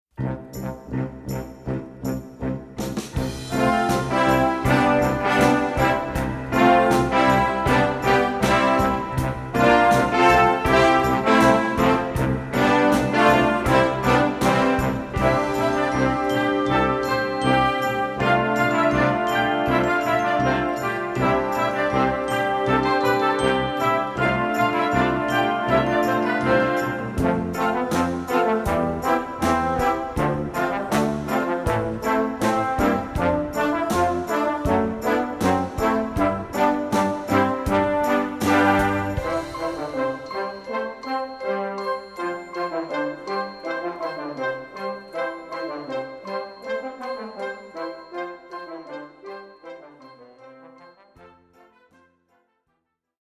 Gattung: Rock für Jugendmusik
Besetzung: Blasorchester